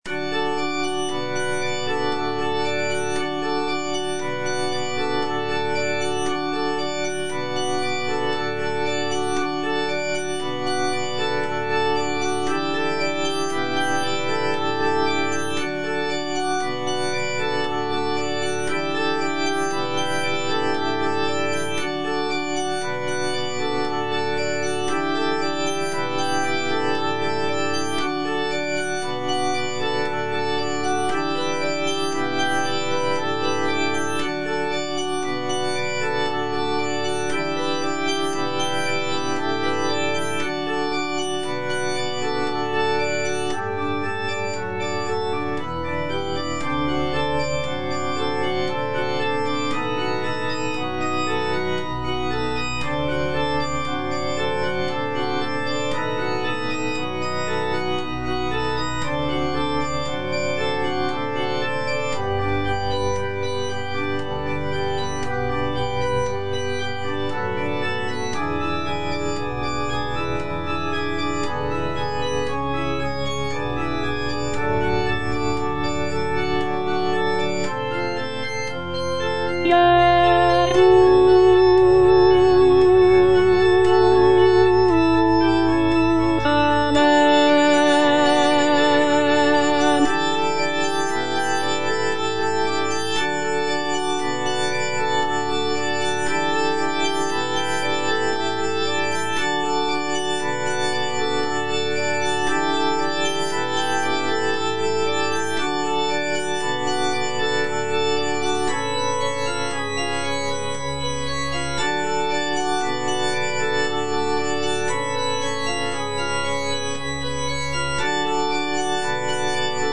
version with a smaller orchestra
Alto (Voice with metronome) Ads stop